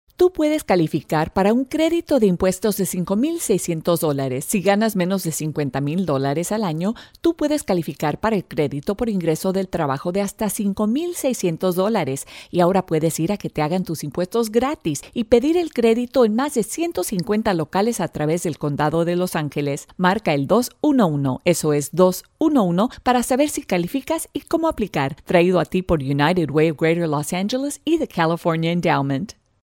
Below, 4 Radio Spots (English and Spanish versioins) for The United Way